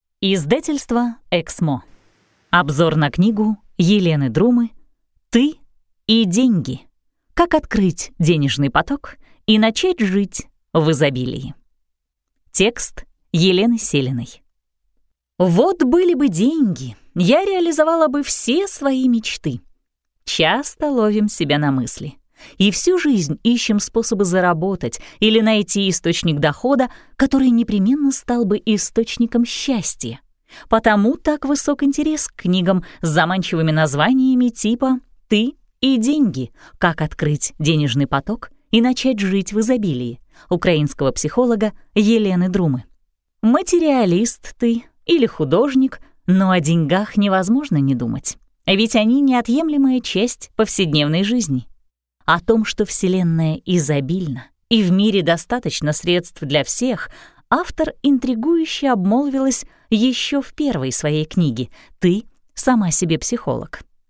Аудиокнига Саммари книги «Ты и деньги» | Библиотека аудиокниг